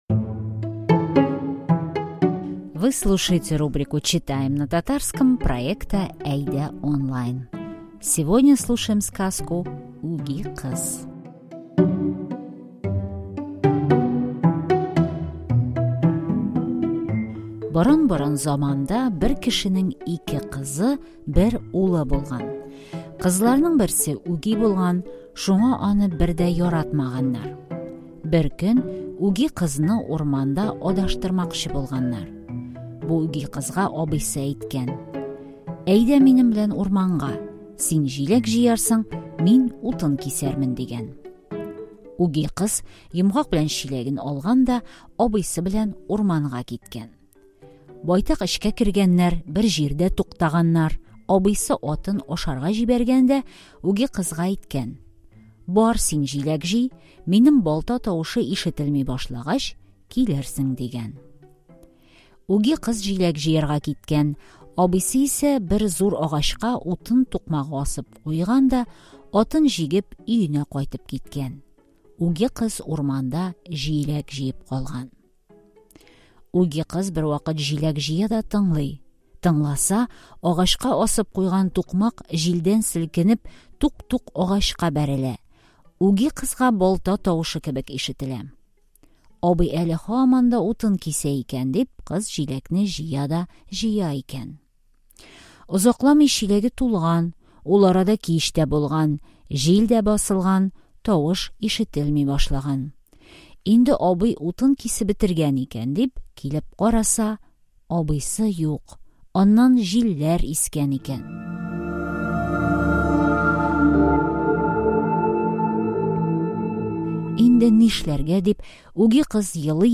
Сегодня читаем одну из самых известных татарских сказок – Үги кыз (падчерица). Текст сказки адаптирован для изучающих язык, мы записали красивую аудиосказку, добавили перевод ключевых фраз и тест.